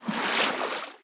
water-02.ogg